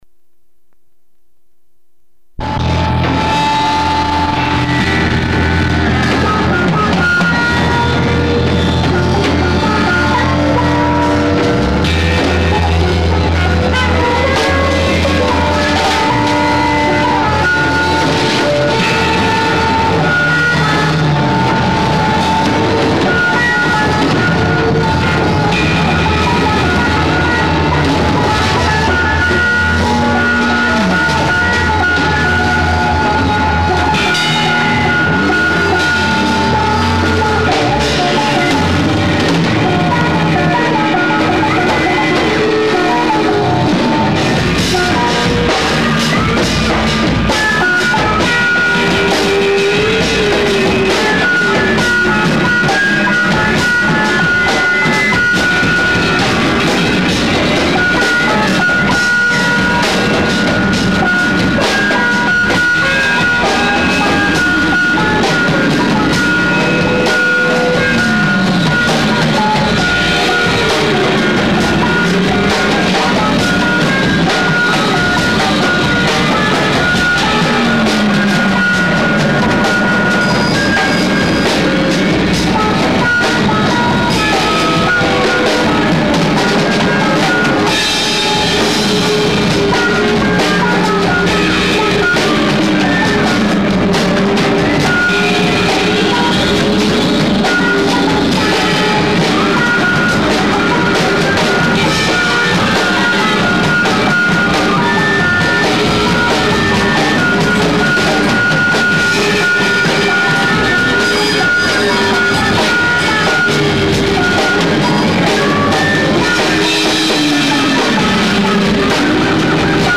Location: 5 Corners Saloon